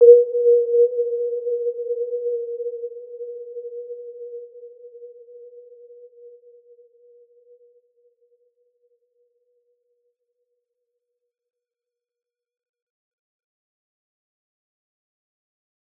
Warm-Bounce-B4-mf.wav